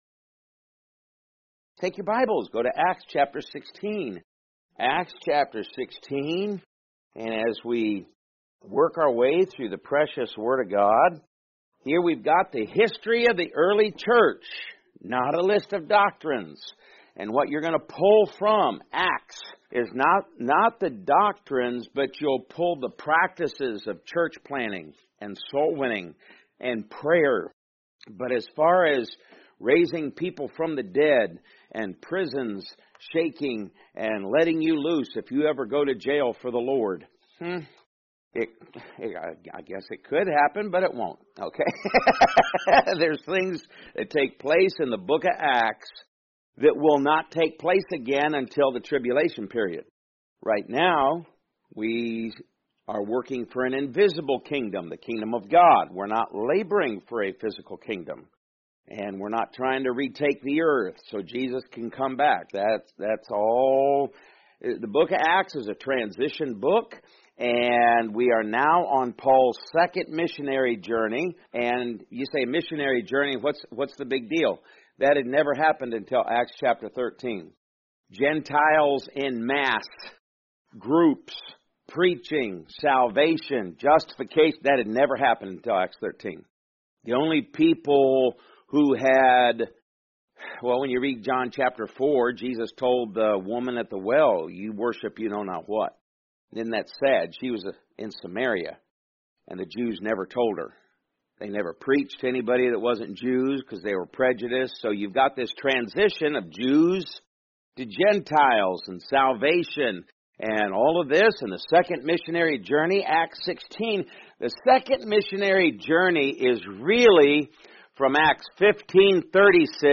Podcast (sermon-podcast): Play in new window | Download